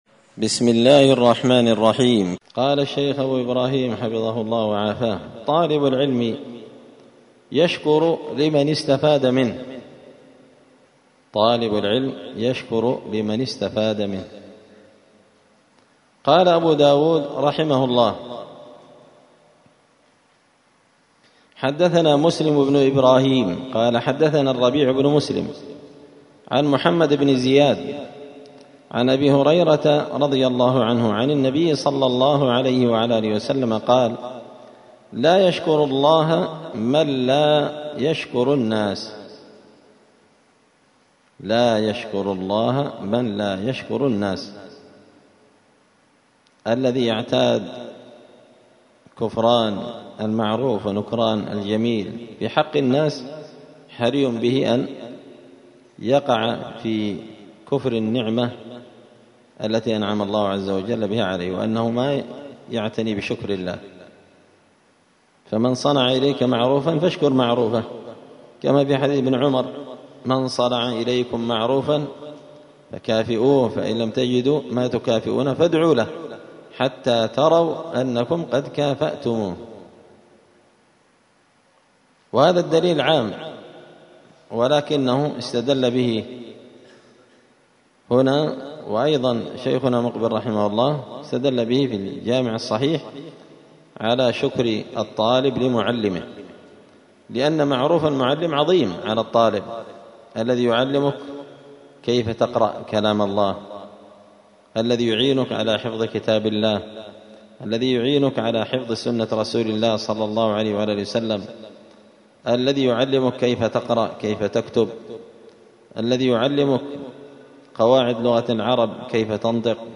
دار الحديث السلفية بمسجد الفرقان بقشن المهرة اليمن
*الدرس الخامس والثلاثون (35) طالب العلم يشكر لمن استفاد منه*